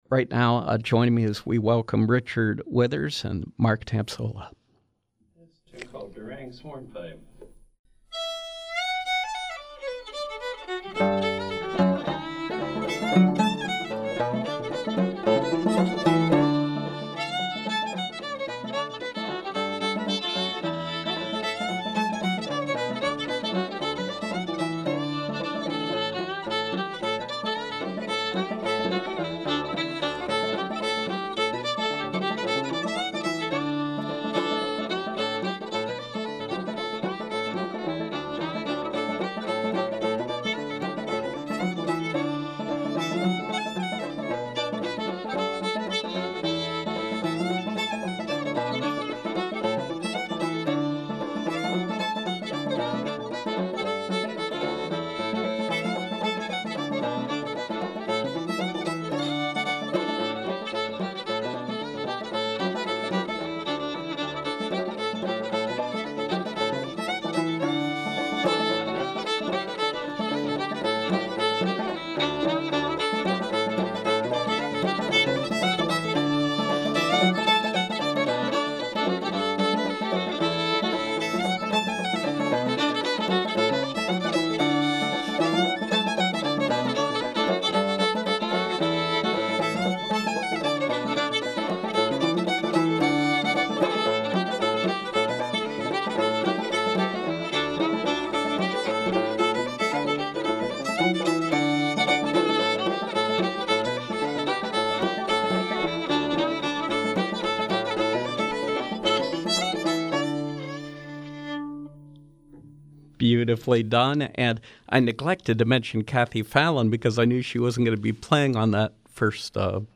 Live Music: Old Time Music of Southwestern PA
Old-time music